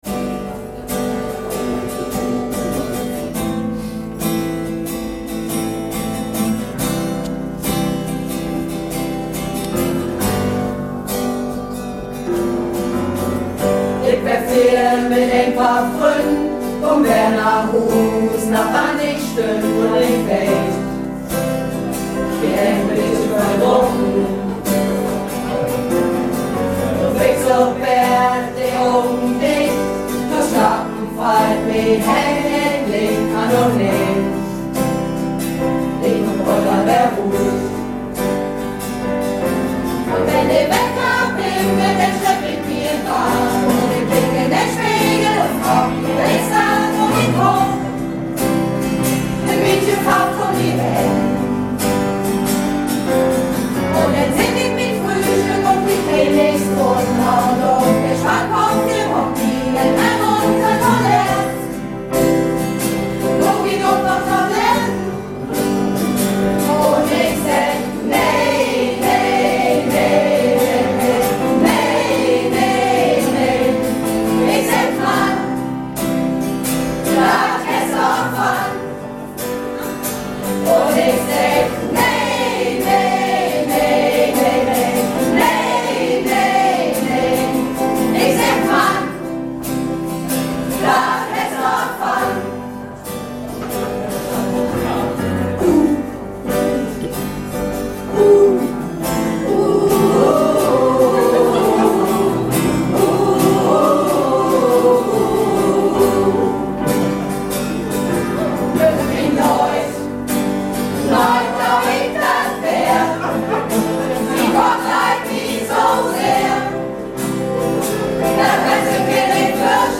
Malle Diven - "Dinner Concertante" in Pilsum am 17.02.12